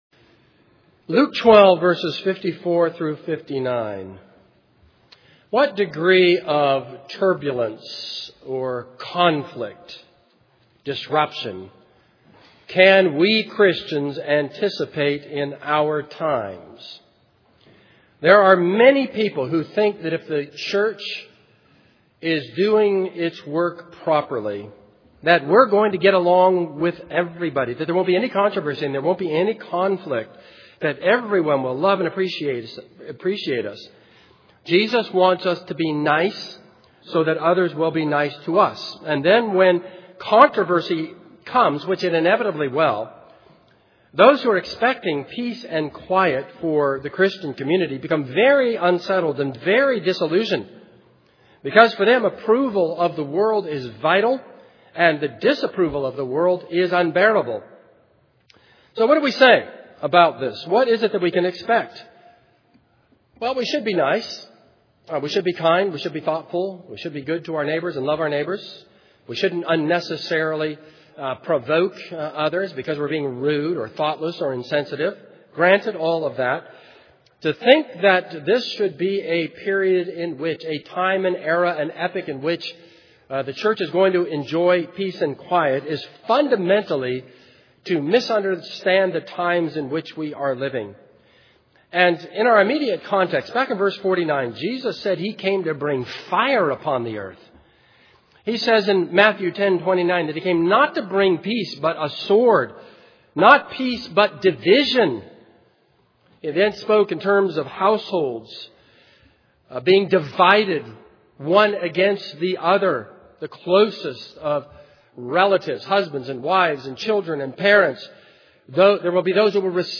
This is a sermon on Luke 12:54-59.